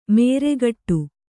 ♪ nēregaṭṭu